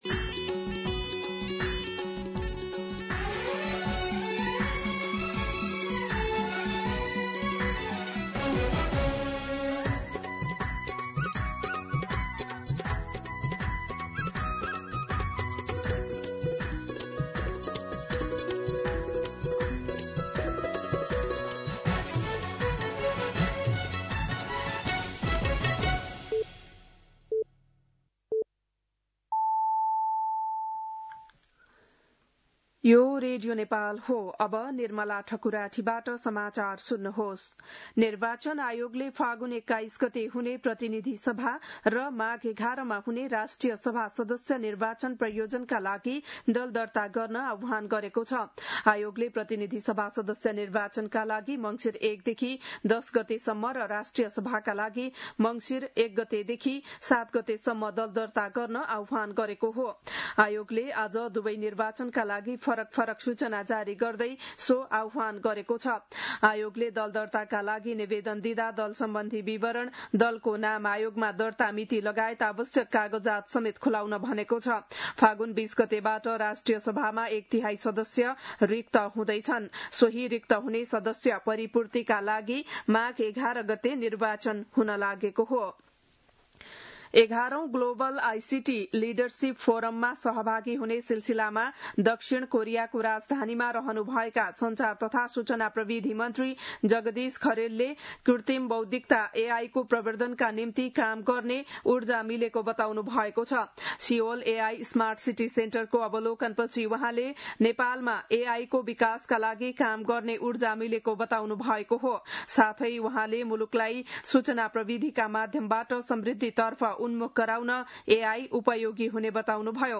बिहान ११ बजेको नेपाली समाचार : २८ कार्तिक , २०८२
11-am-News-7-28.mp3